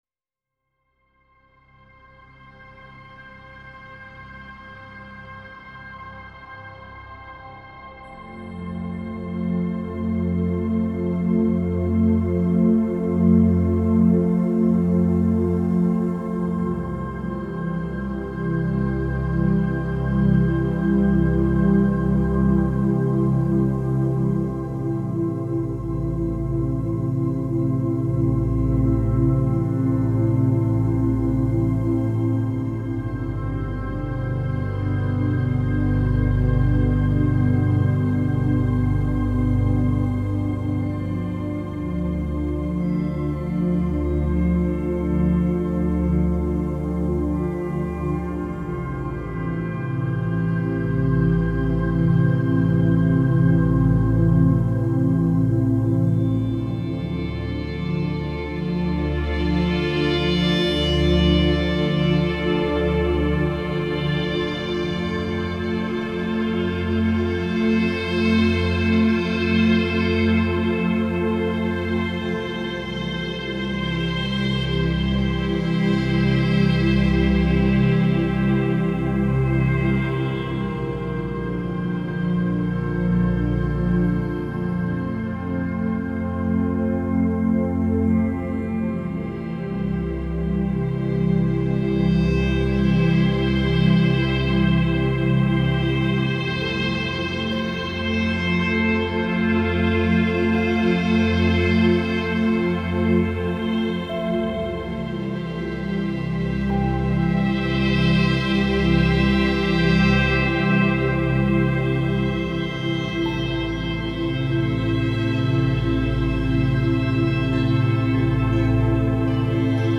Relaxation music